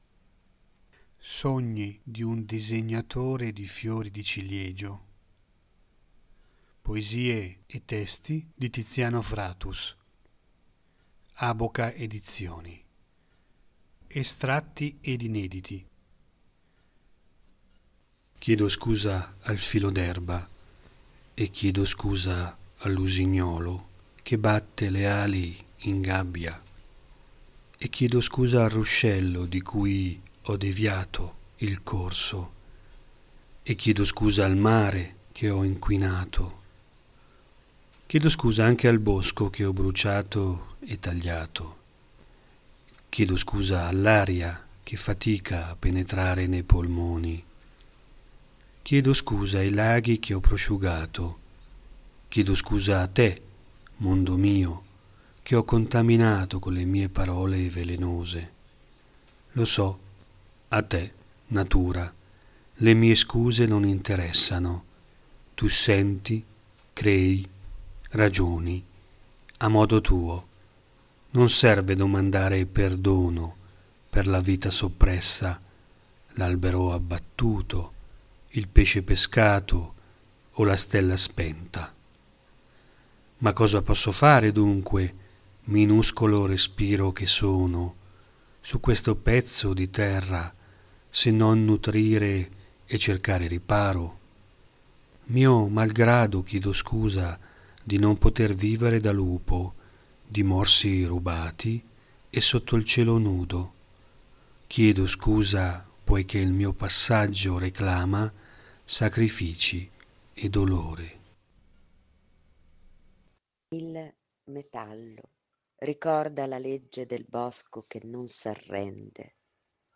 Estratti ed inediti [durata 5.00] letture